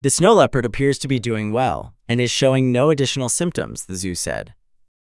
Text-to-Speech
Synthetic